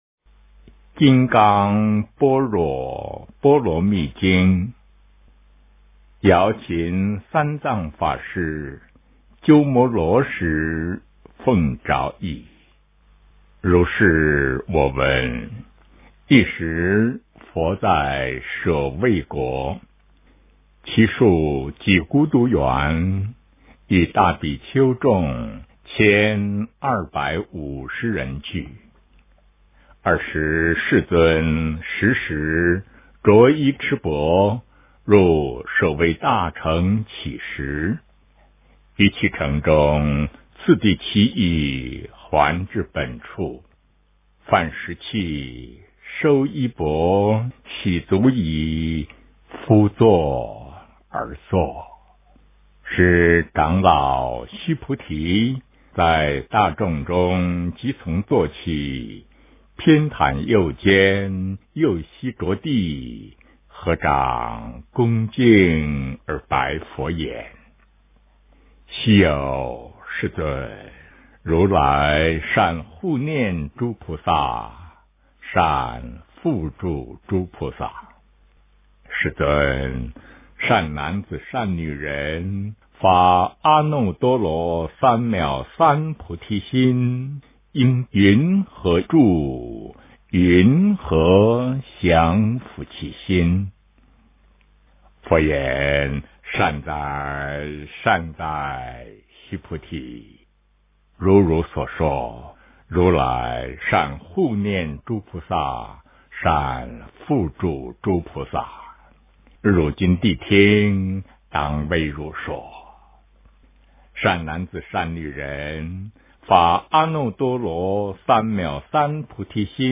诵经
佛音 诵经 佛教音乐 返回列表 上一篇： 大般若波罗蜜多经第483卷 下一篇： 大般若波罗蜜多经第486卷 相关文章 空山静水--佚名 空山静水--佚名...